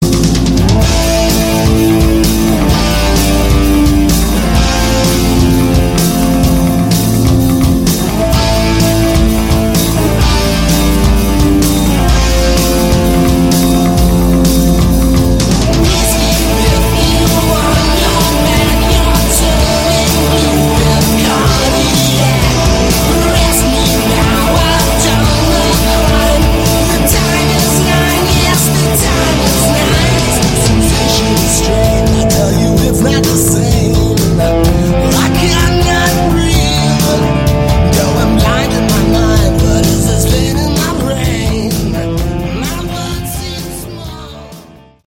Category: Heavy Metal/Glam